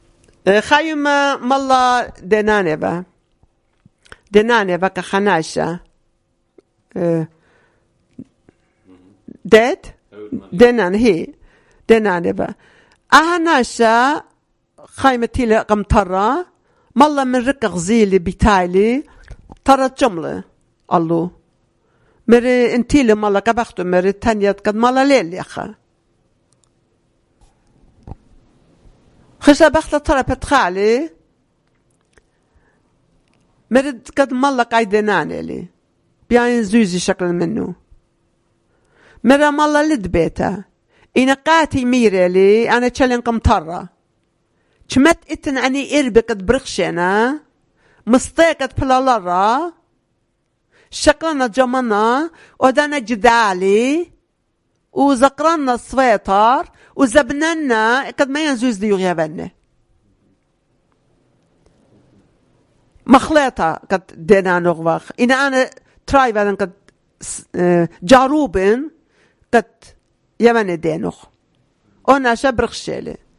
Urmi, Christian: A Sweater to Pay Off a Debt